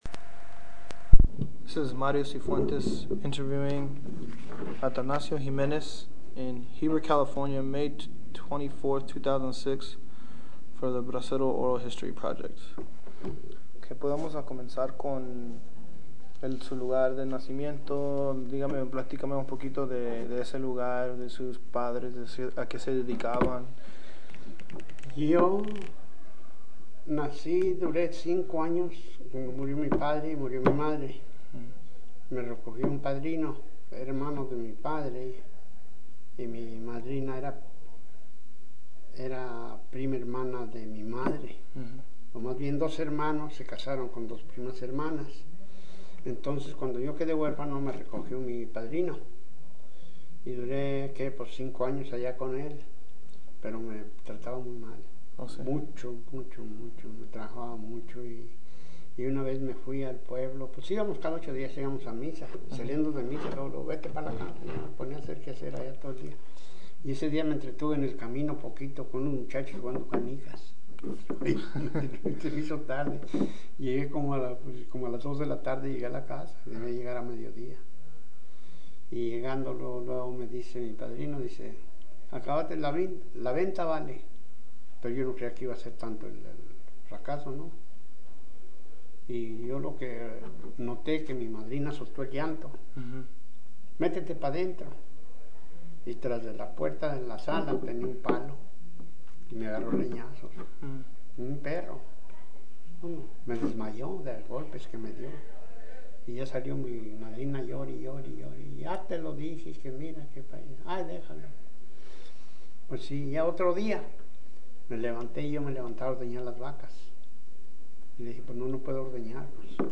Location Heber, CA Original Format Mini Disc